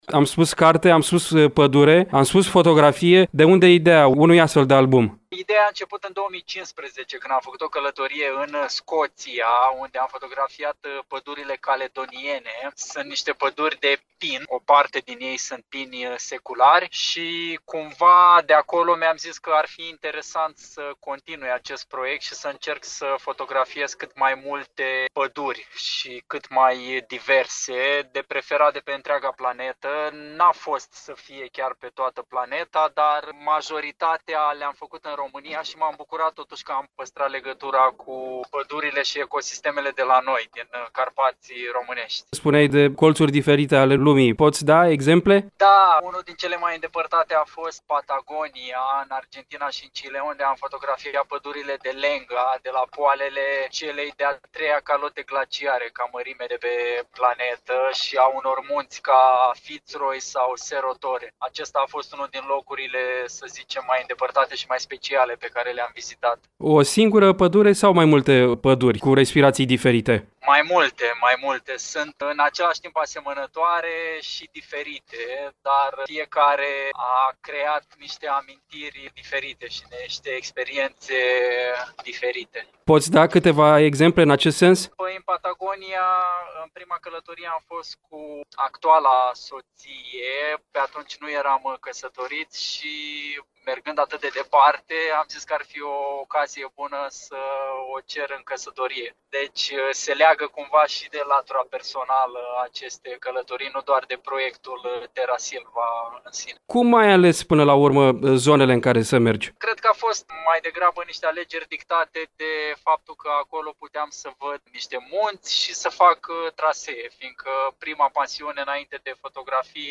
Varianta audio a dialogului: